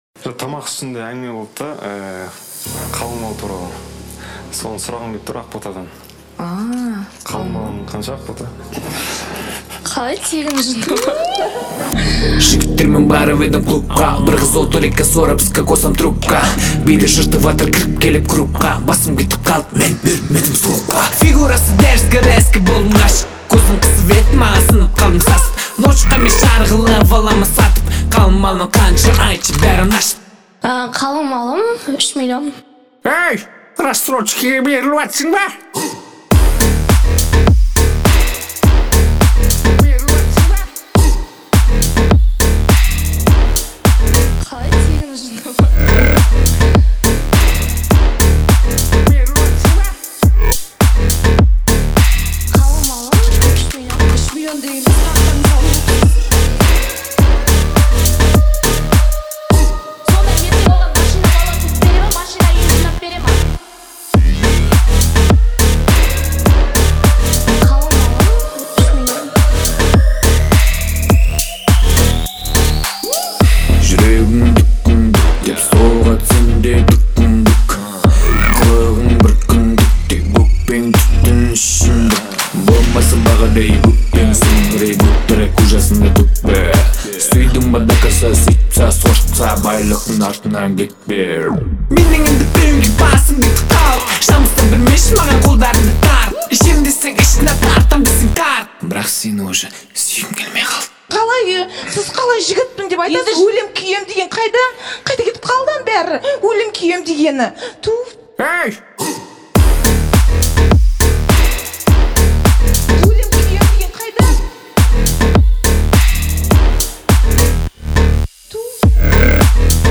который добавляет уникальные биты и атмосферные эффекты.